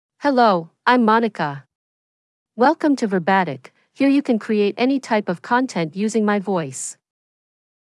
FemaleEnglish (United States)
MonicaFemale English AI voice
Monica is a female AI voice for English (United States).
Voice sample
Monica delivers clear pronunciation with authentic United States English intonation, making your content sound professionally produced.